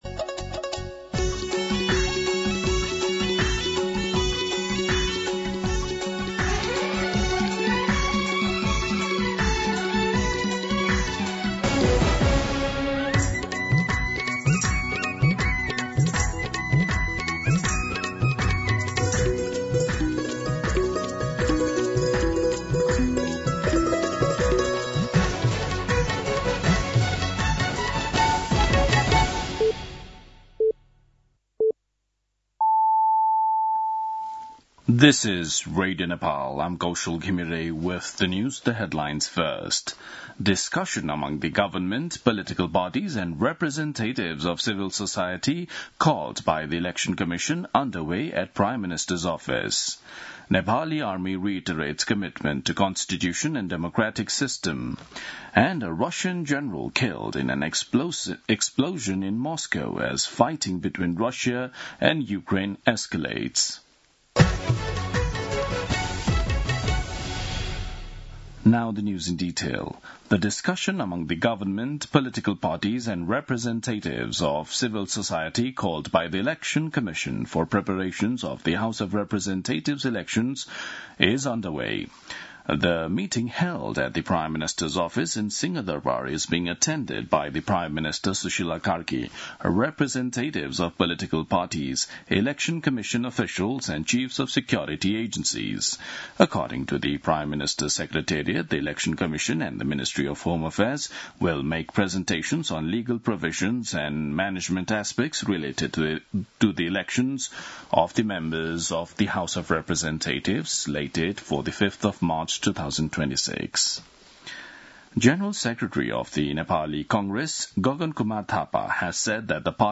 दिउँसो २ बजेको अङ्ग्रेजी समाचार : ७ पुष , २०८२